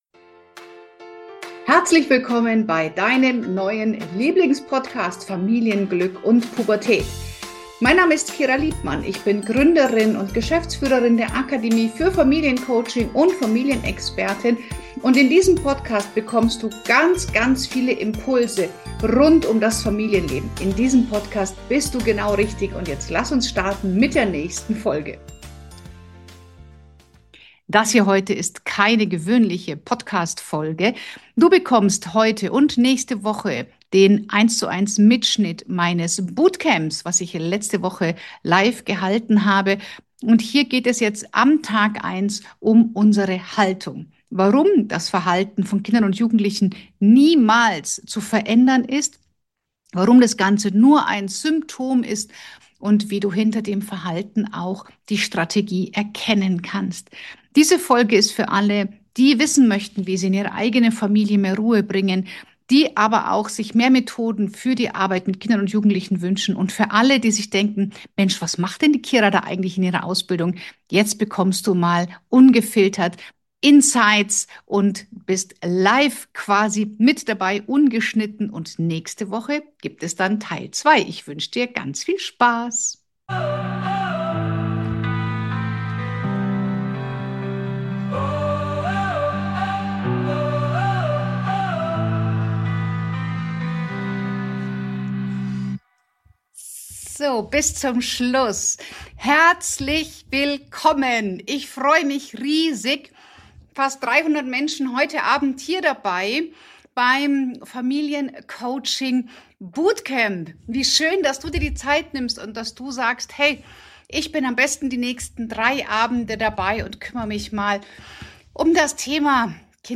Du hörst hier die Aufzeichnung von Tag 1 unseres ersten Tages des Bootcamps und genau dort passiert für viele der größte Perspektivwechsel.